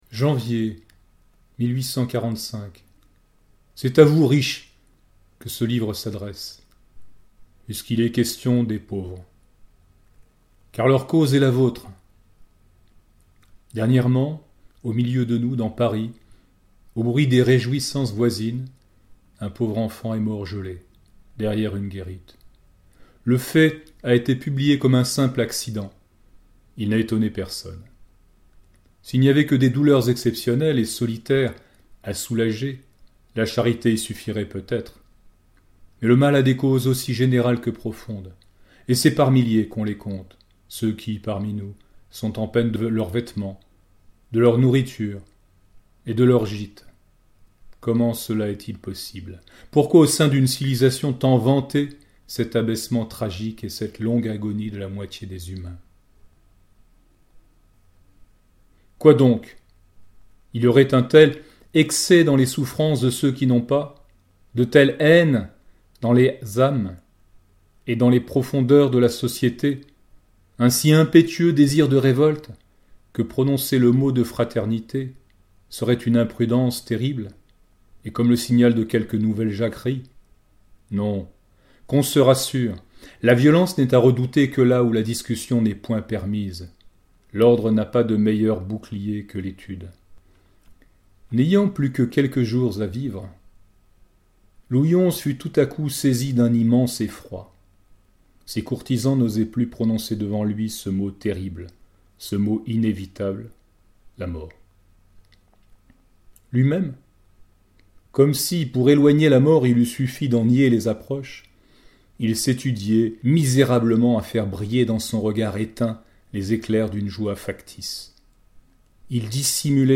SlowReading
* SlowReading : lecture aux lèvres, qui ralentit une pensée toujours pressée et galopante